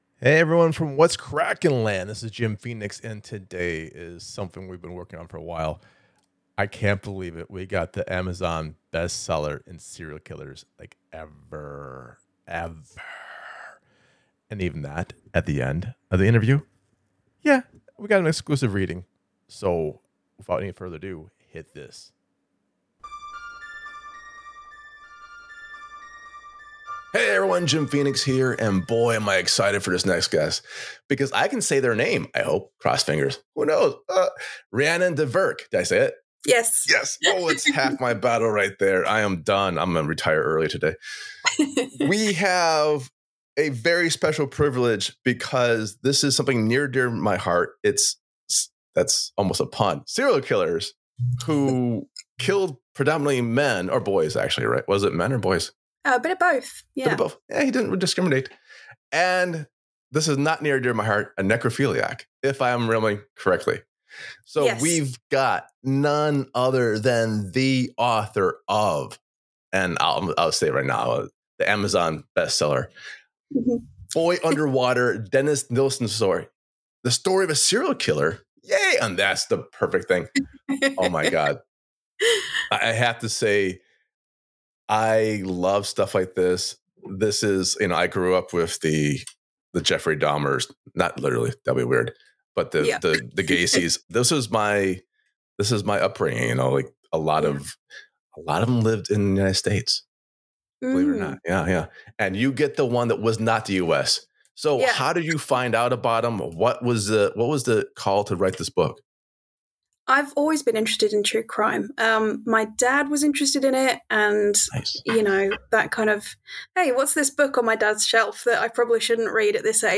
Author Interview